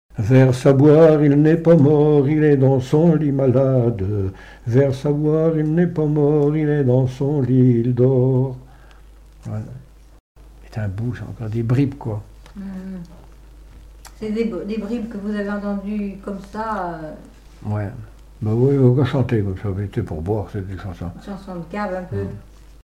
Mémoires et Patrimoines vivants - RaddO est une base de données d'archives iconographiques et sonores.
bachique
Pièce musicale inédite